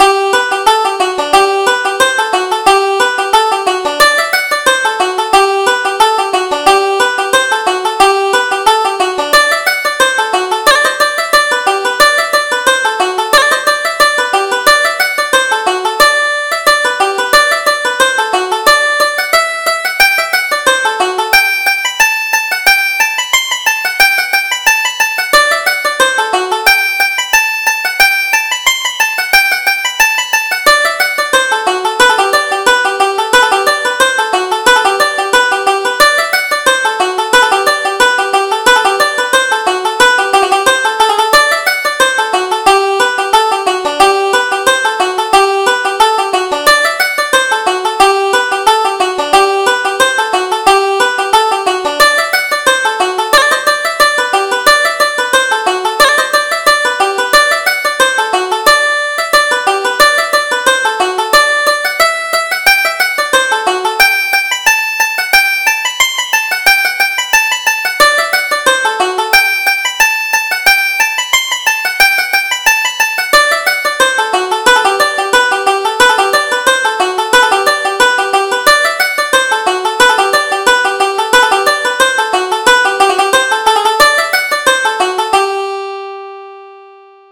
Reel: Trim the Velvet